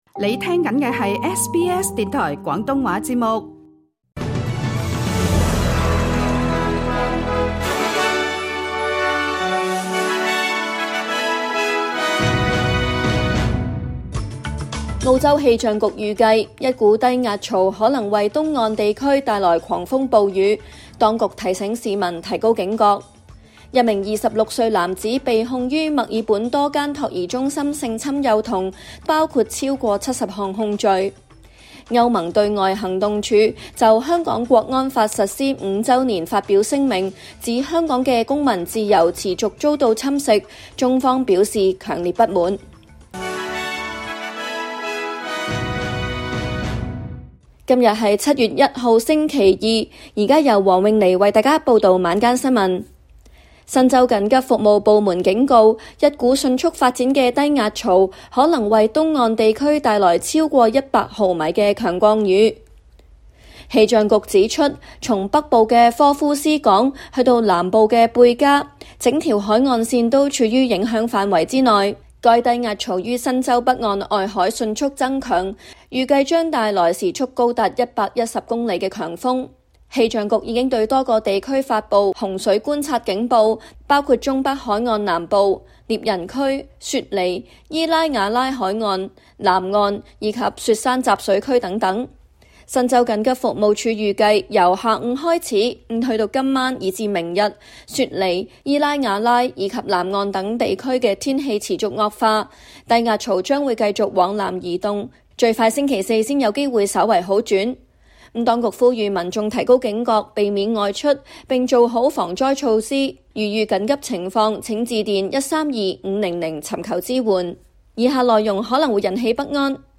SBS廣東話晚間新聞